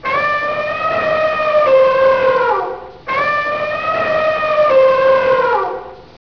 elefant.wav